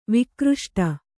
♪ vikruṣṭa